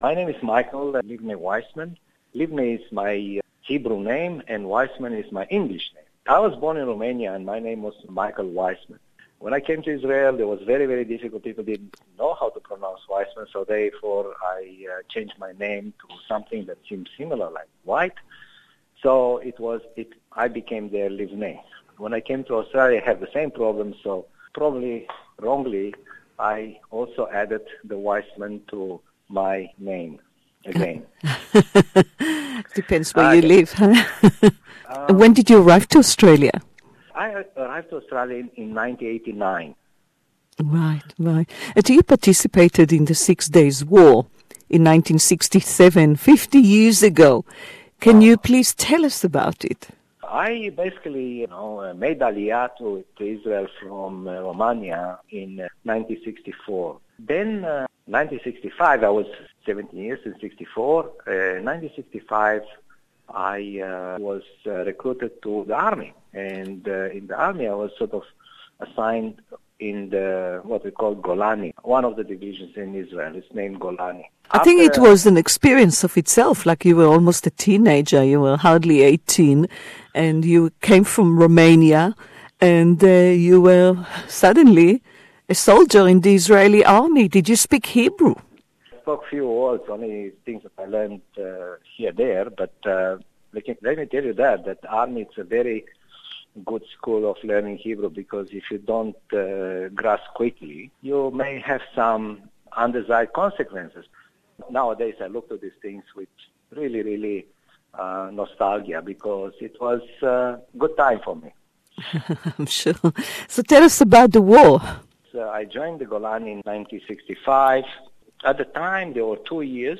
who took part in the Six-Day War as a young soldier in the IDF This interview is in English